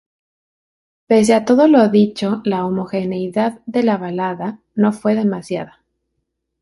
Pronunciado como (IPA)
/ˈdit͡ʃo/